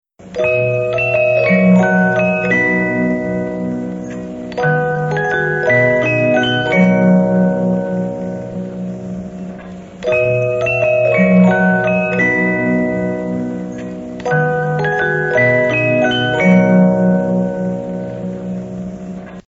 ... im Nahbereich aufgenommen